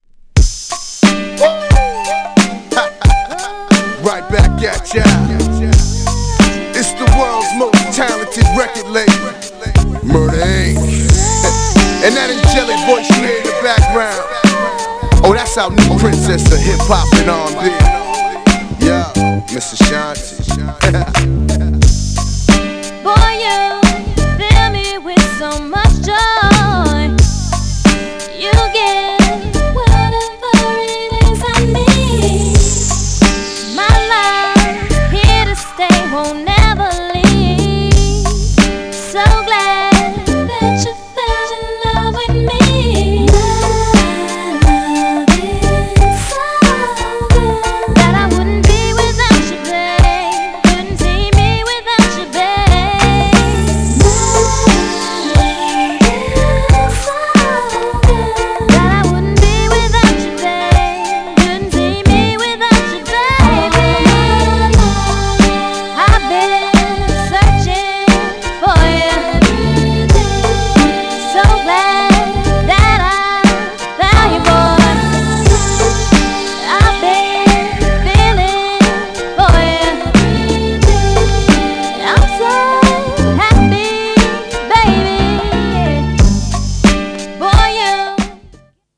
GENRE R&B
BPM 101〜105BPM